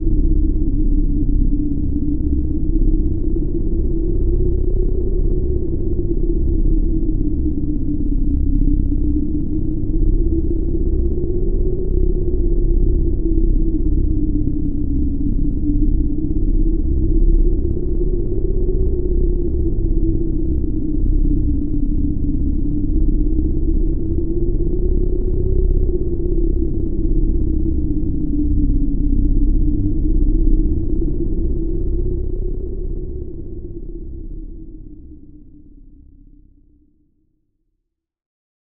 Index of /90_sSampleCDs/Club_Techno/Atmos
Atmos_12_C1.wav